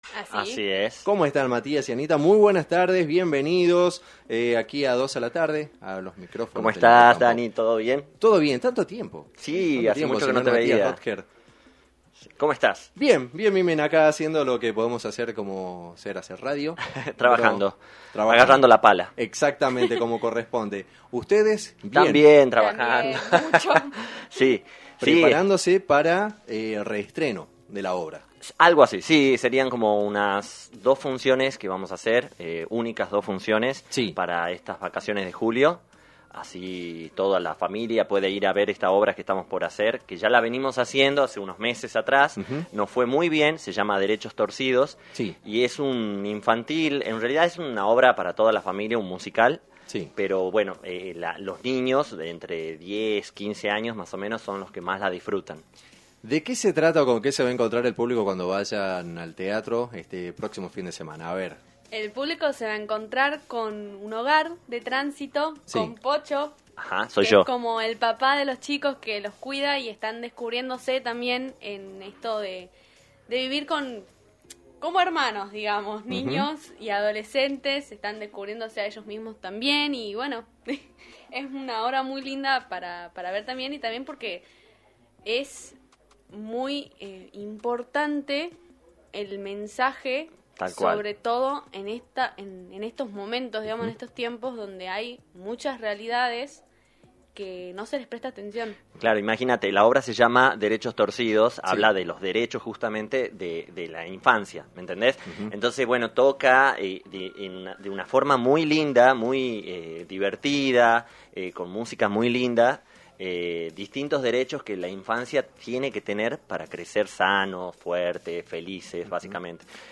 visitaron los estudios de Rock&Pop FM 106.9 en el programa «Dos a la Tarde»